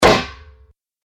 دانلود آهنگ دعوا 37 از افکت صوتی انسان و موجودات زنده
دانلود صدای دعوا 37 از ساعد نیوز با لینک مستقیم و کیفیت بالا
جلوه های صوتی